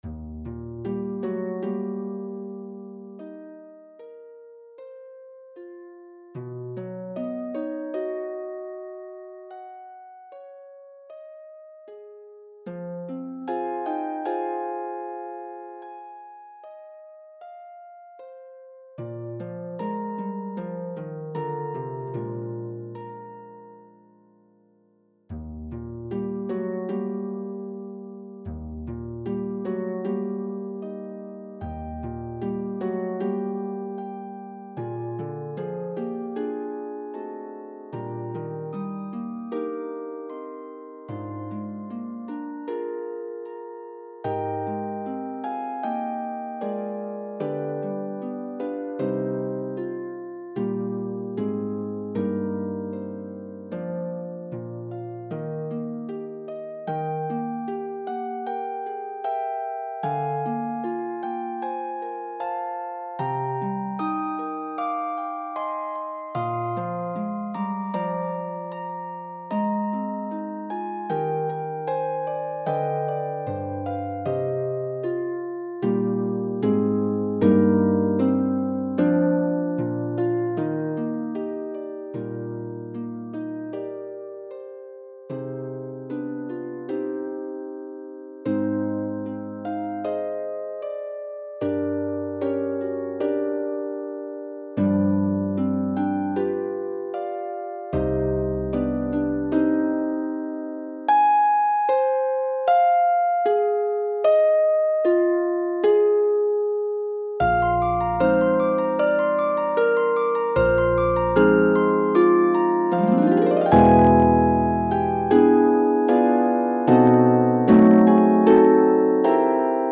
for two pedal harps